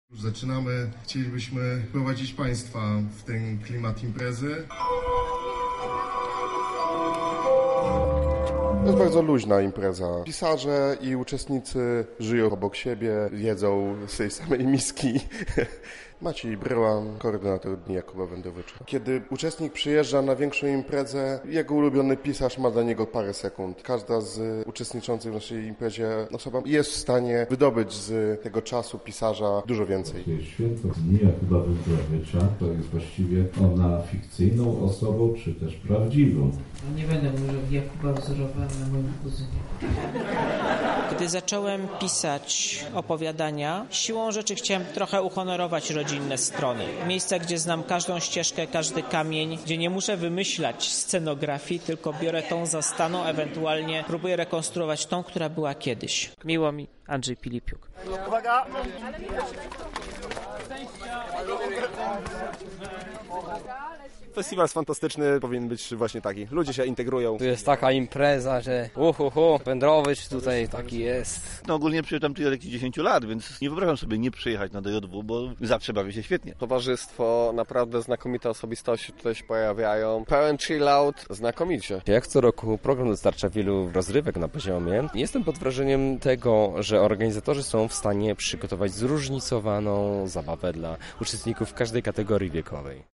Wśród uczestników wydarzenia byli także nasi reporterzy: